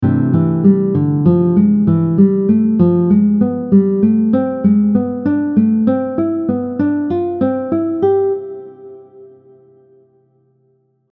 Example 5 using triads
Here, we’re alternating 3 notes for each of the two chords we derive from this scale in different inversions, the C Major 6 chord and the D diminished 7 chord.
Major-6-diminished-scale-example-5.mp3